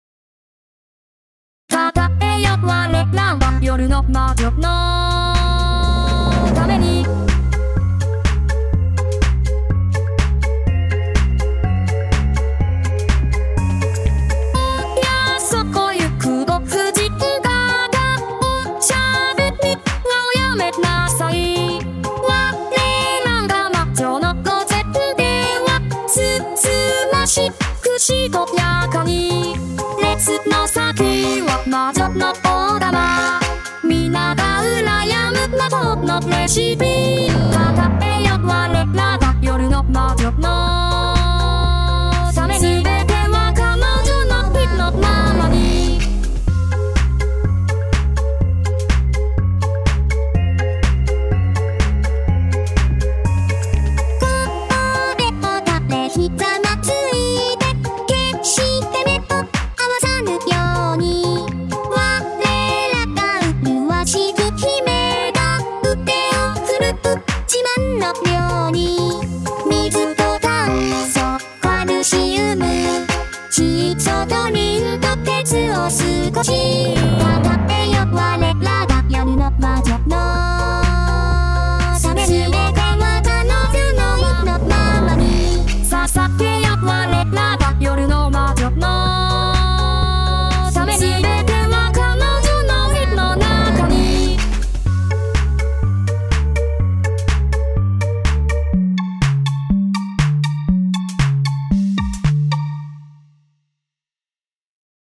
【VOCALOID】 mp3 DL ♪